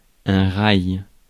Ääntäminen
Synonyymit glissoir Ääntäminen France (Paris): IPA: [ɛ̃ ʁaj] Tuntematon aksentti: IPA: /ʀaj/ IPA: /ʁɑj/ Haettu sana löytyi näillä lähdekielillä: ranska Käännös Ääninäyte Substantiivit 1. rail US Suku: m .